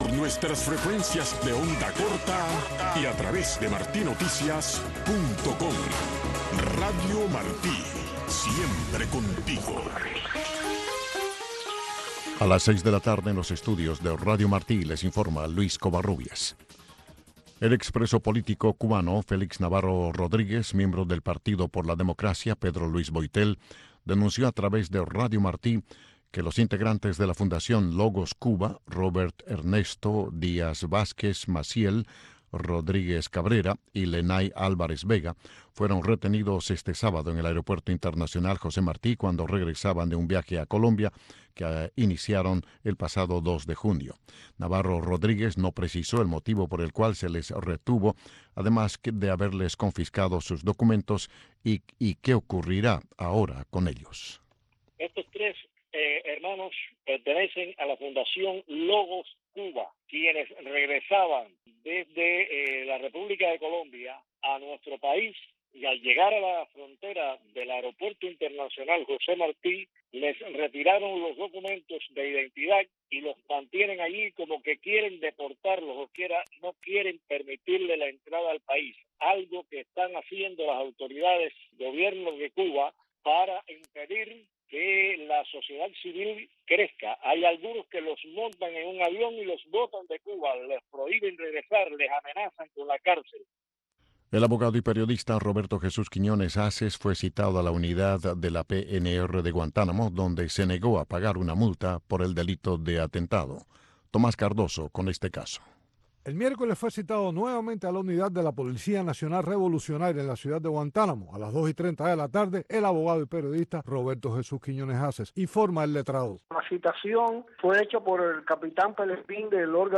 Radio Marti presenta Tribuna Libre. Los acontecimientos que omitieron tus libros de historia, contados por uno de sus protagonistas. Un programa conducido por el Doctor: Luis Conté Agüero.